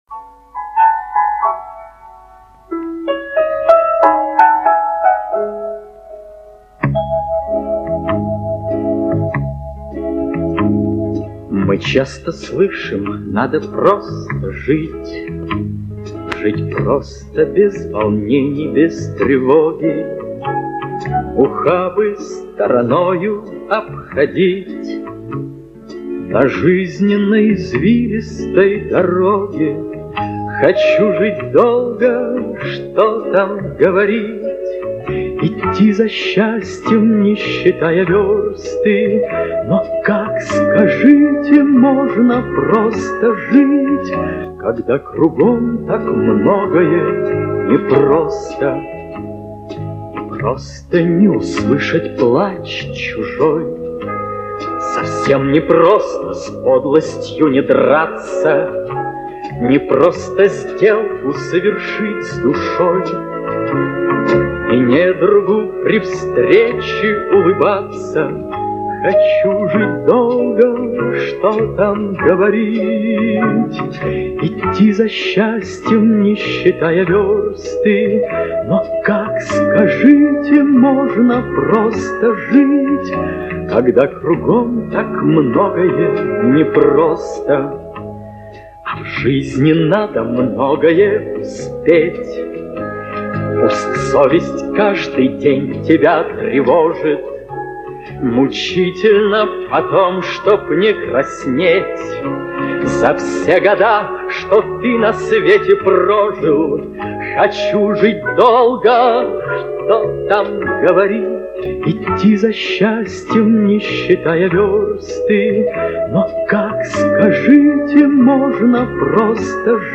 Песня из кинофильма